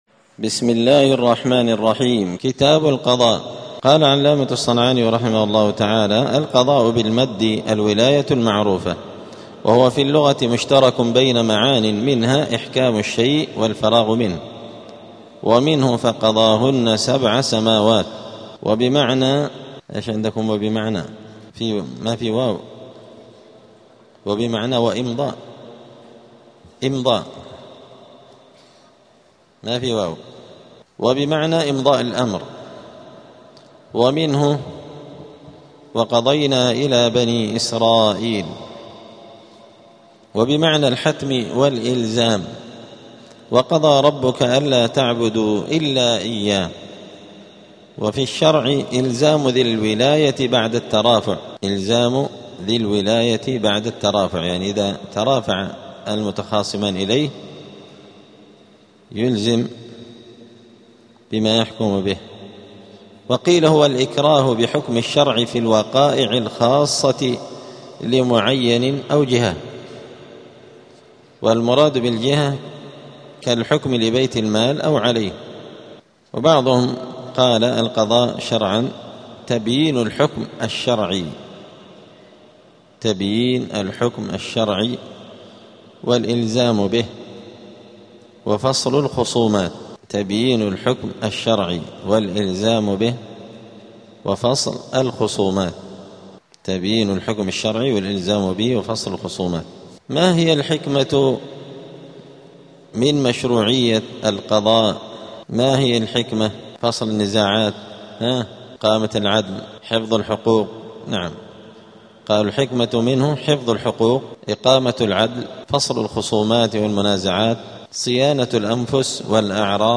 *الدرس الأول (1) {تعريف القضاء}*